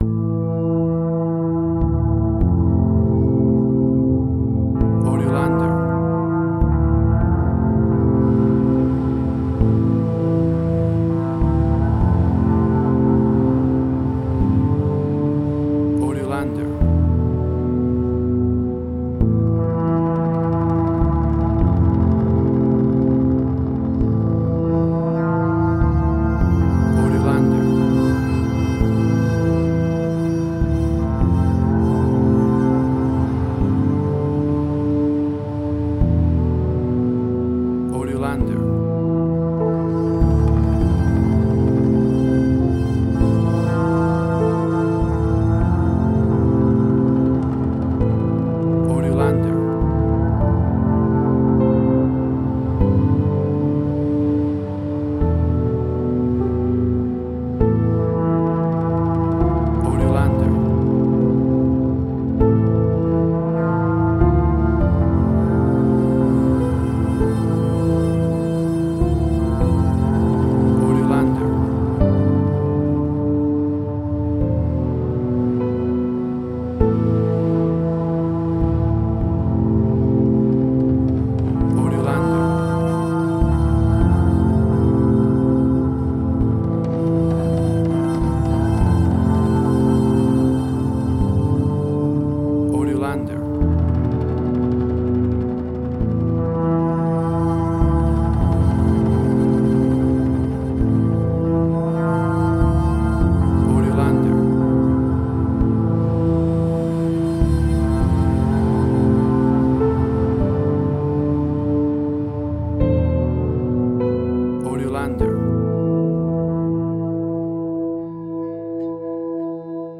Tempo (BPM): 53